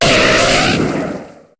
Cri de Ponyta dans Pokémon HOME.